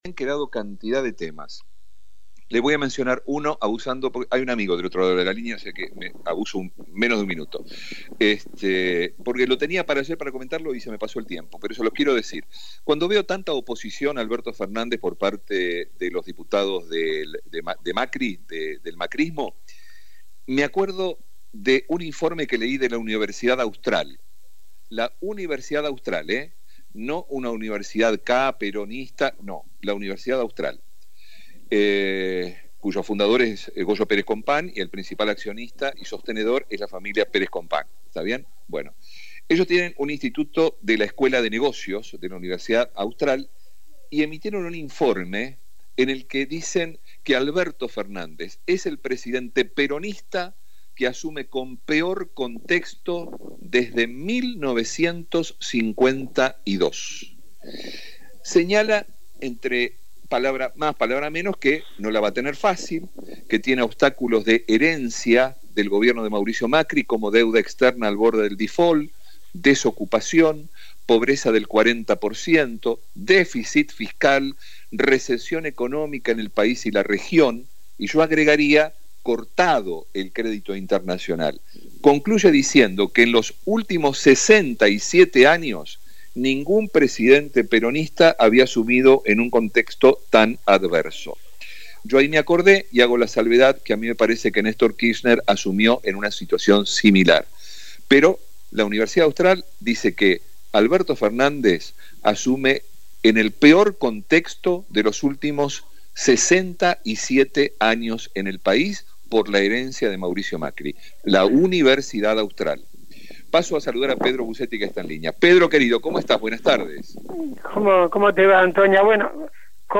DEUCO, en reportaje